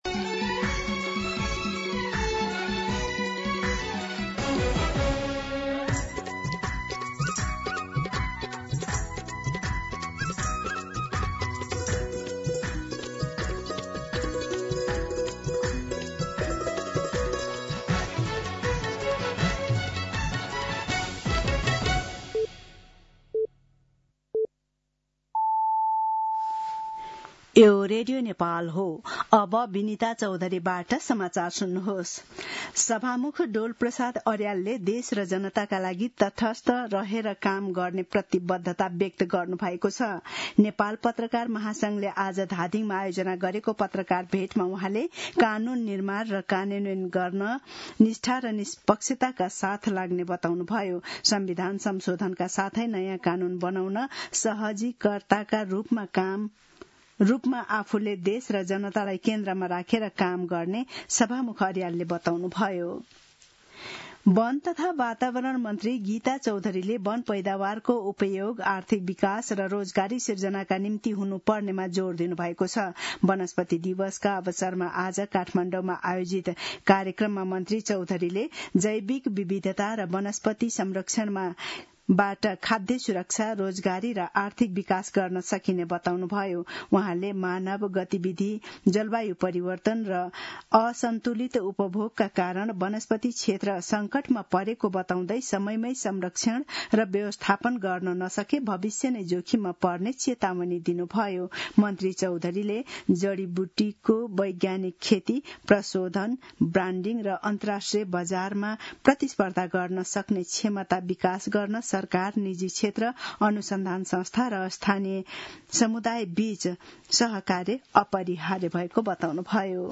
दिउँसो ४ बजेको नेपाली समाचार : २९ चैत , २०८२